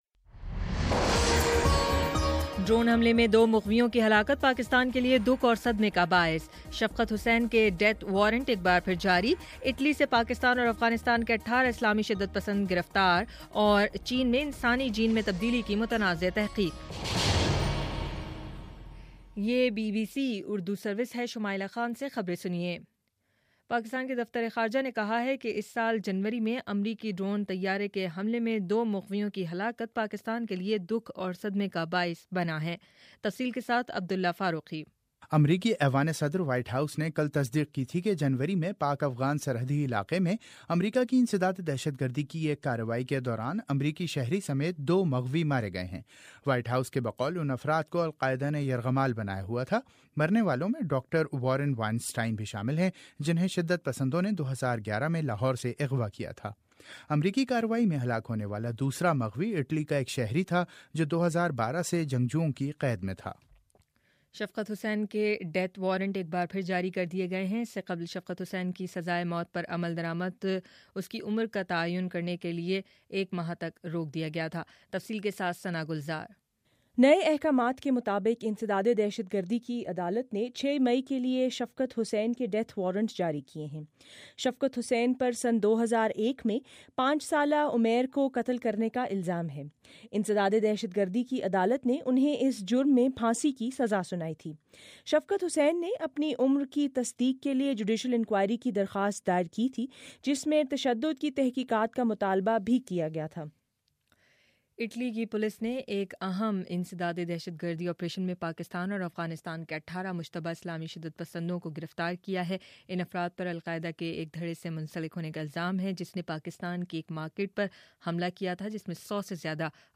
اپریل 24: شام سات بجے کا نیوز بُلیٹن